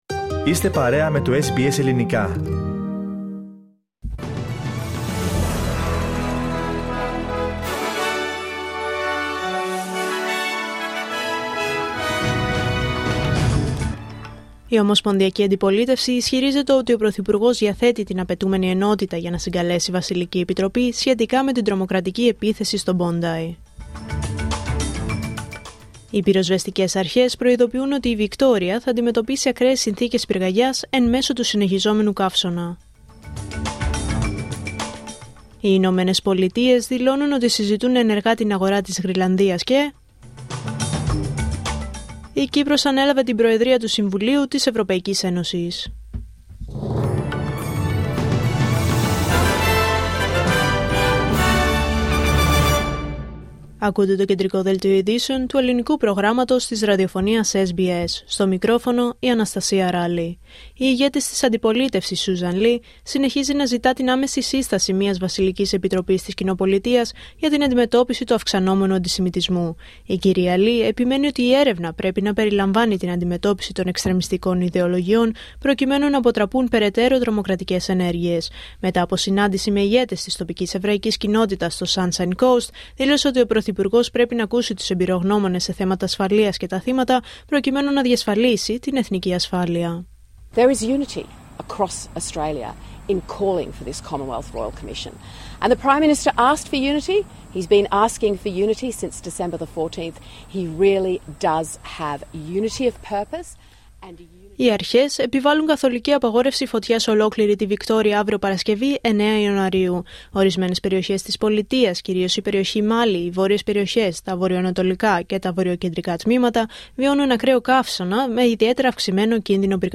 Δελτίο Ειδήσεων Πέμπτη 8 Ιανουαρίου 2026